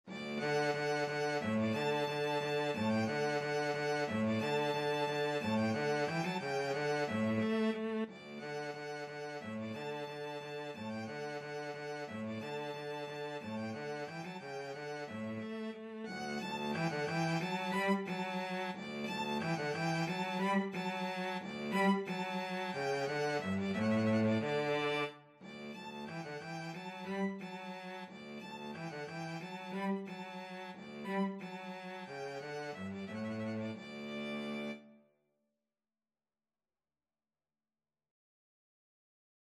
Free Sheet music for Violin-Cello Duet
D major (Sounding Pitch) (View more D major Music for Violin-Cello Duet )
=180 Vivace (View more music marked Vivace)
4/4 (View more 4/4 Music)
Classical (View more Classical Violin-Cello Duet Music)
vivaldi_spring_vnvc.mp3